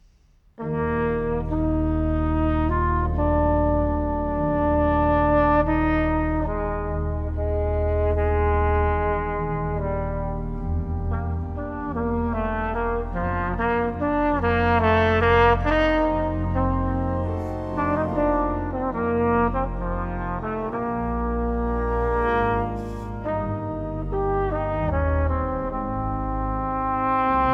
Trompete und Flügelhorn
Orgel und Klavier
Percussion